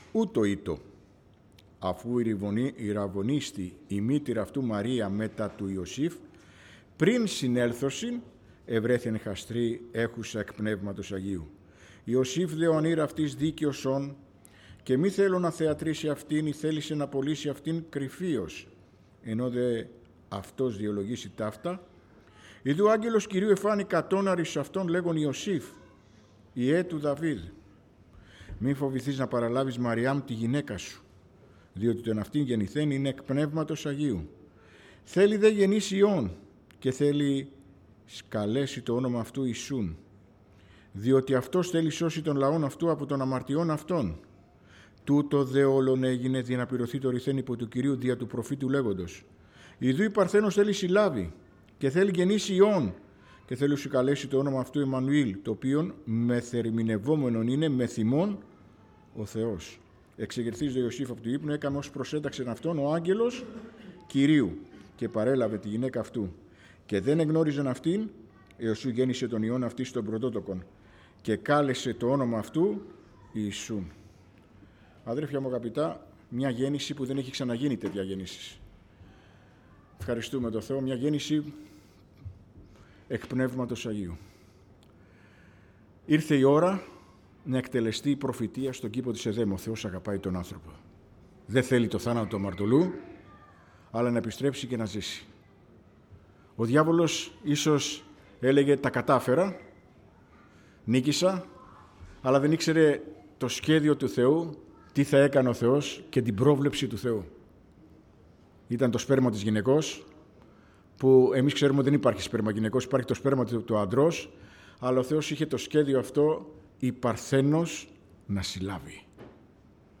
Series: Κήρυγμα Ευαγγελίου